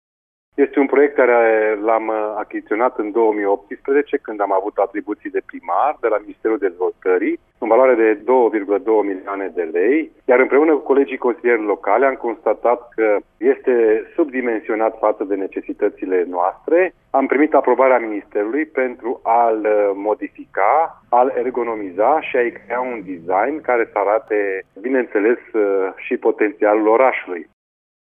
Primarul oraşului Ghimbav, Ionel Fliundra: